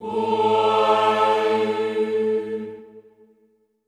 Index of /90_sSampleCDs/Best Service - Extended Classical Choir/Partition D/HOO-AHH-EHH
HOO-AH  A2-L.wav